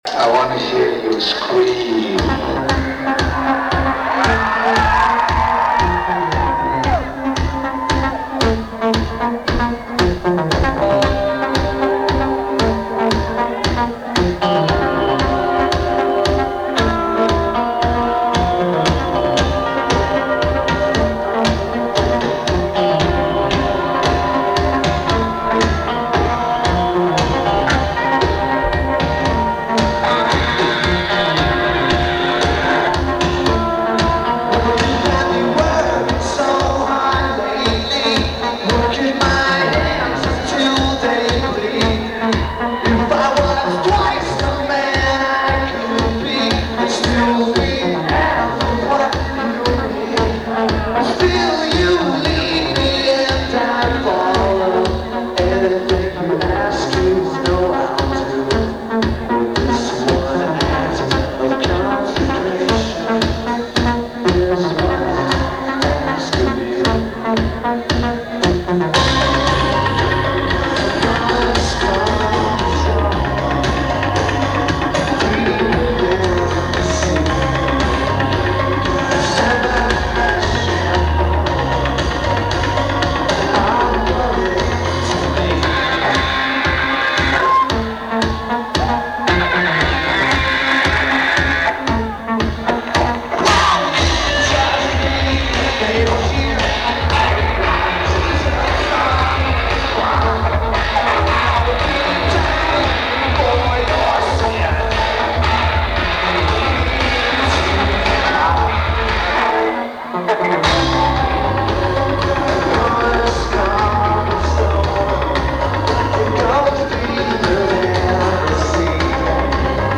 St. Andrews Hall
Lineage: Audio - AUD (Cassette Recorder + Radioshack Mic)